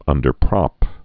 (ŭndər-prŏp)